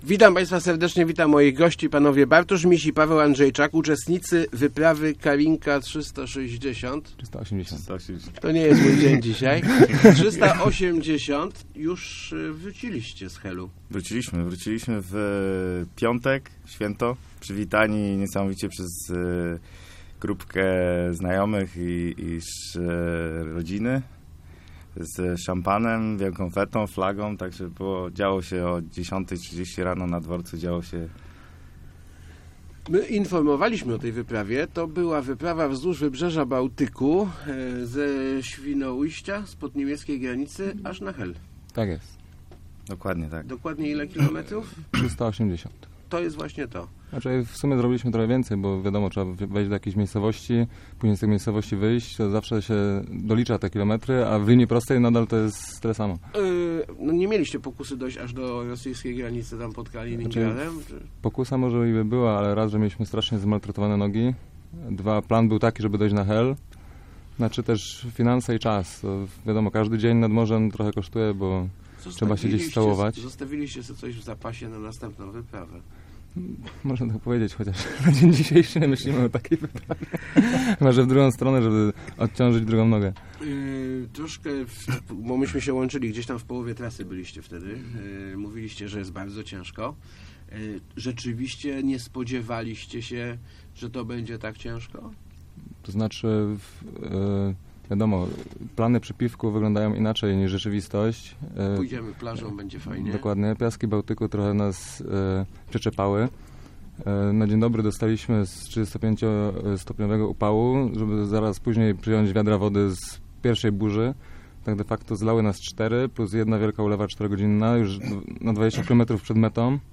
Marsz na granicy pla�y i wody da� si� im mocno we znaki. -Lekarz, który udziela� nam pomocy powiedzia�, �e "mamy z deklem" - mówi� ze �miechem w�drowcy.